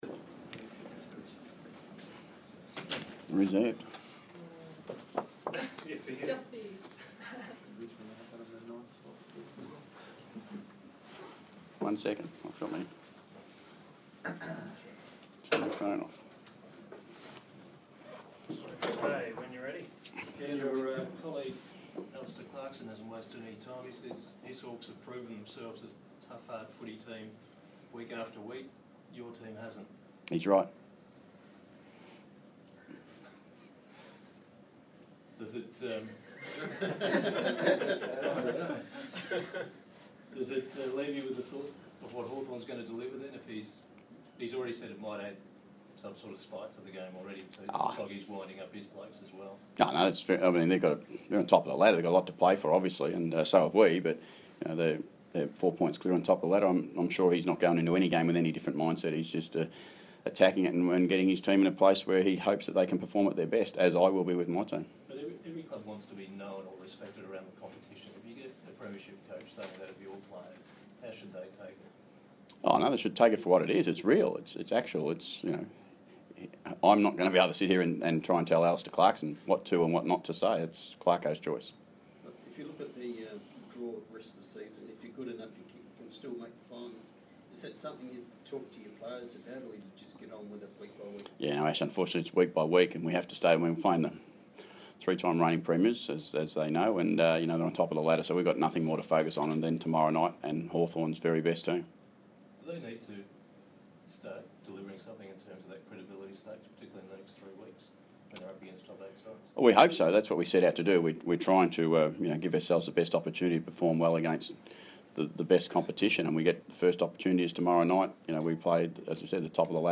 Ken Hinkley Post-match Press Conference - Wednesday, 6 July, 2016